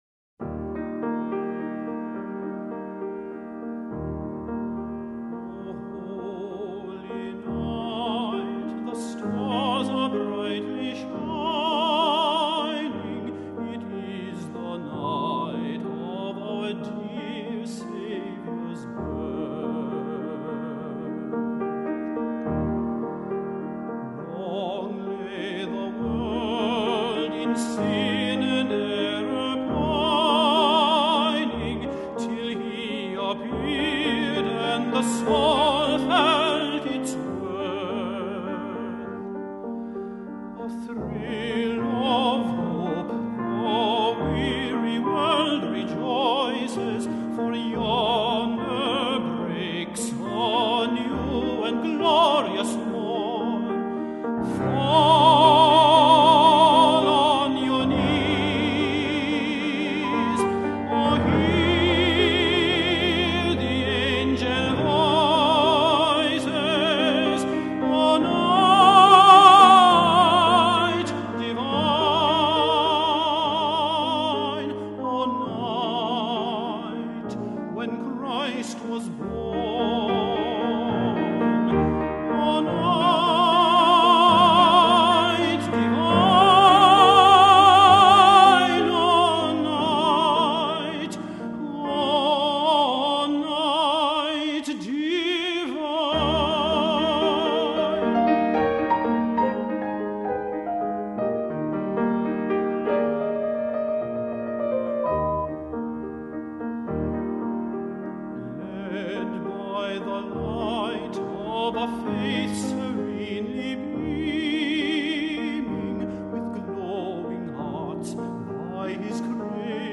Voix Haute et Piano